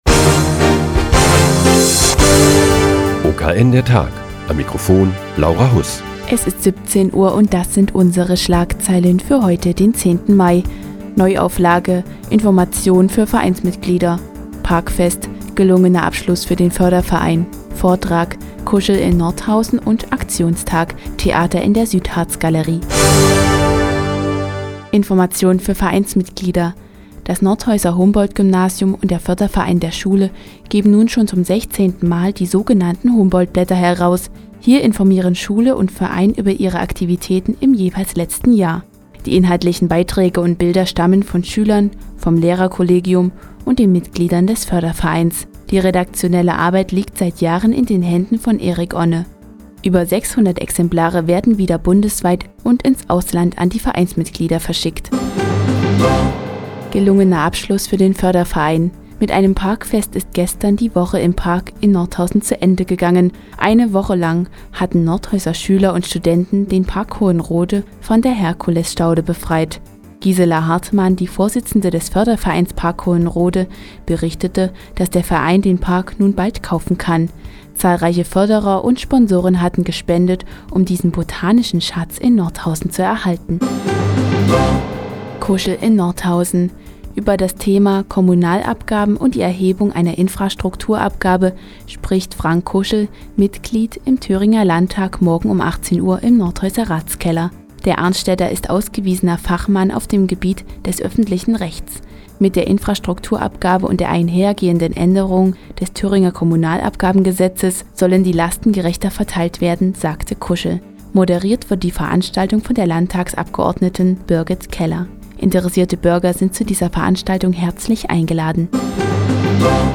Die tägliche Nachrichtensendung des OKN ist nun auch in der nnz zu hören. Heute geht es um das Parkfest im Park Hohenrode und Theater in der Südharzgalerie.